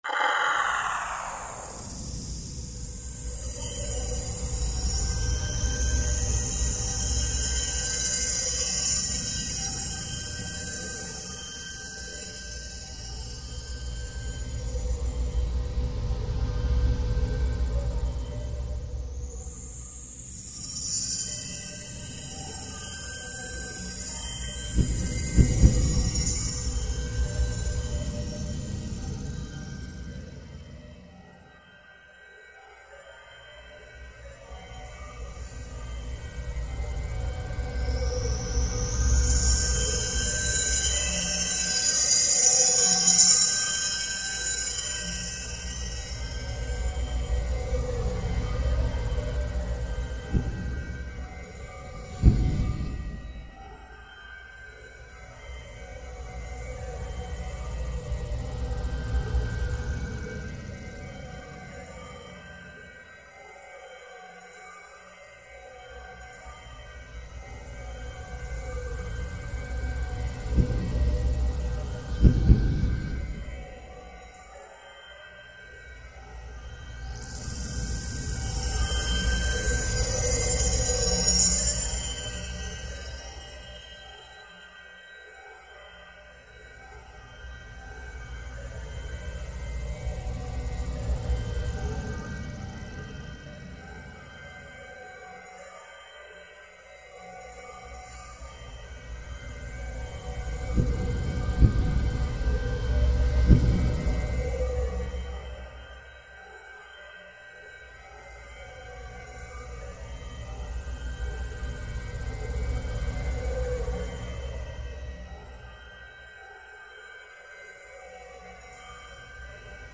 These were then scanned and the noises made by the scanner preserved as audio files, which the composer manipulated in the computer to build tracks.